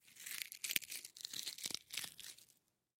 Звуки точилки
Шуршание карандаша о точилку